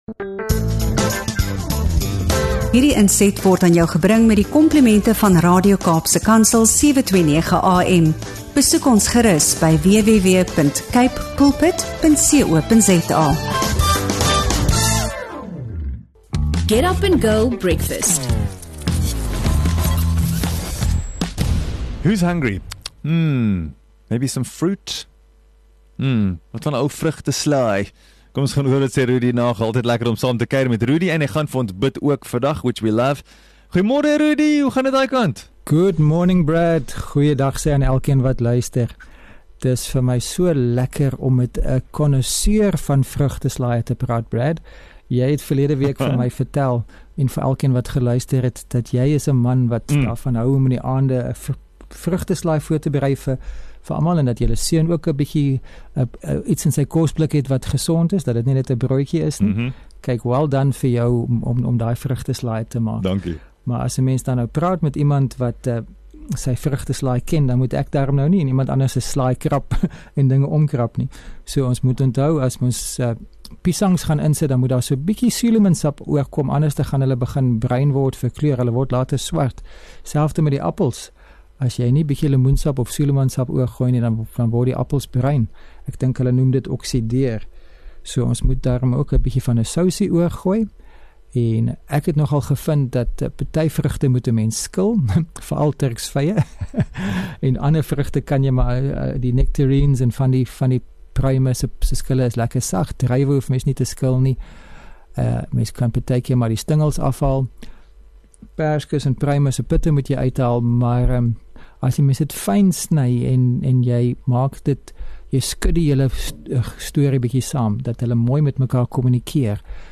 ’n Gesprek oor Ware Vrede